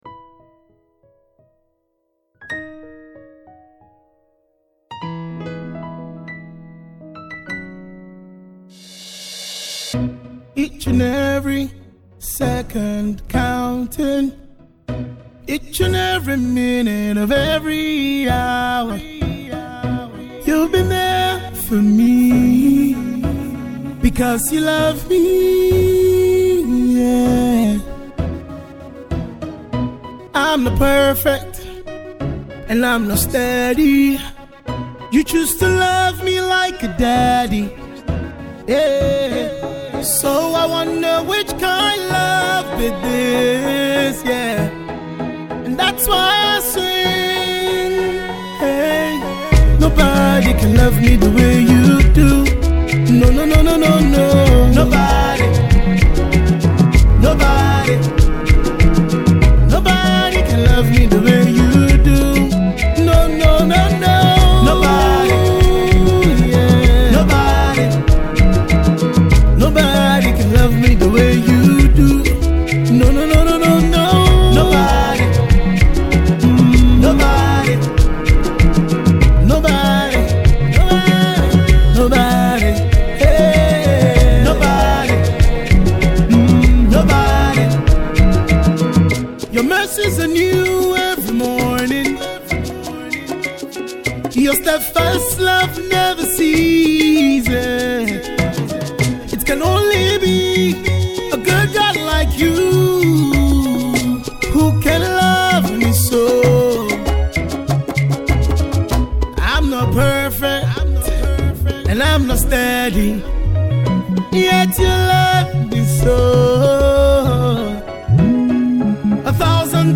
worship tune